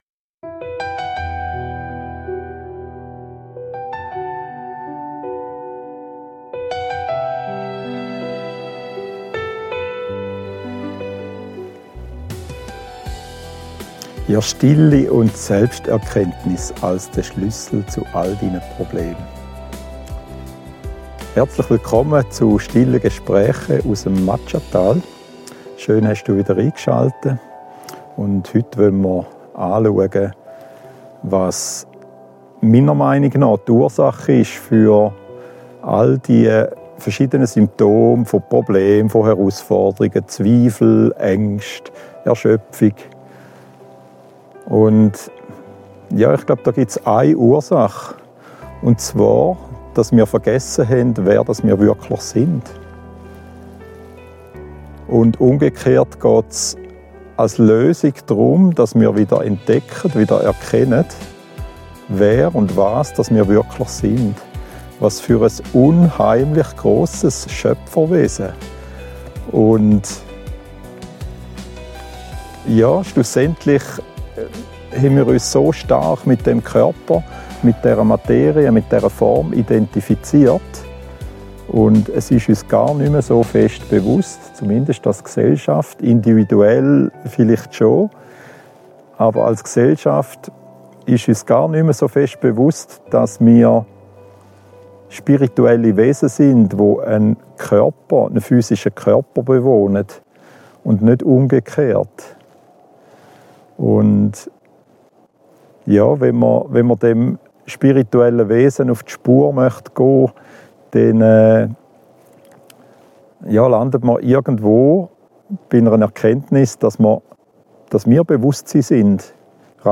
Schweizerdeutsch gesprochen.